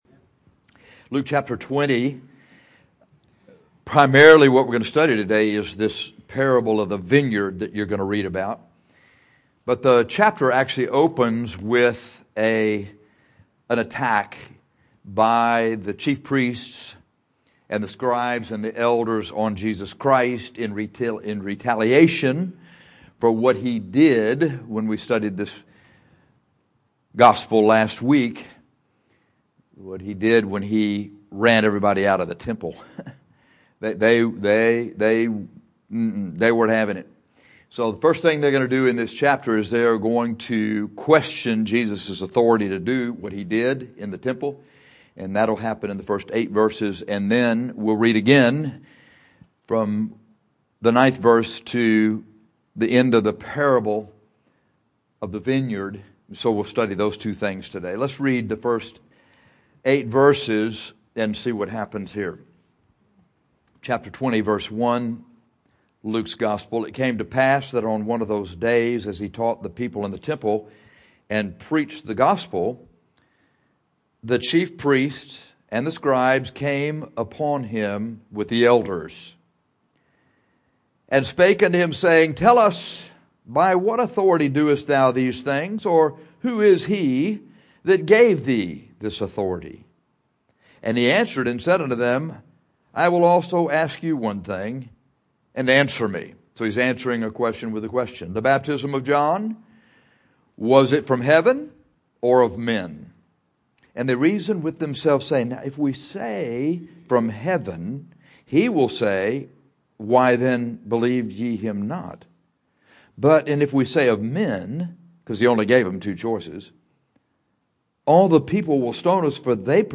In this Sunday school lesson we’ll study the question the chief priests, scribes, and elders asked Jesus and the Parable of the Vineyard.